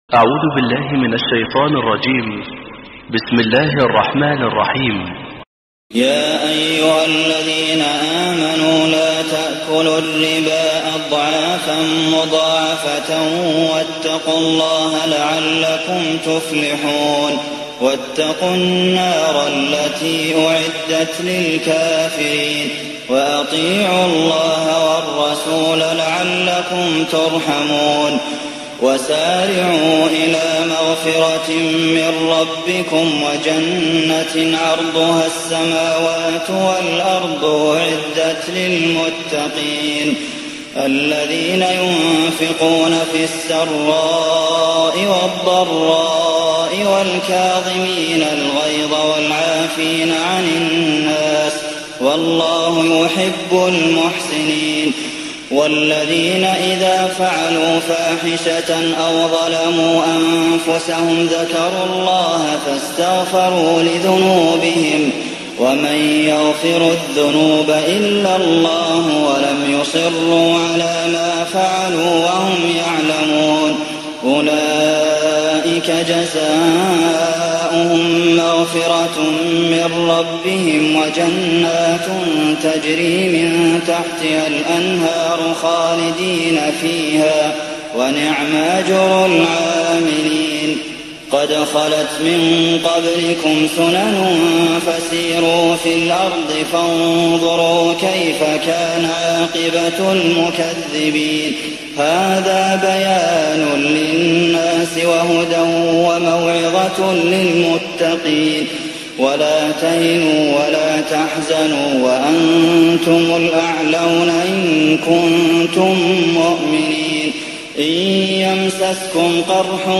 تهجد ليلة 23 رمضان 1419هـ من سورة آل عمران (130-200) Tahajjud 23rd night Ramadan 1419H from Surah Aal-i-Imraan > تراويح الحرم النبوي عام 1419 🕌 > التراويح - تلاوات الحرمين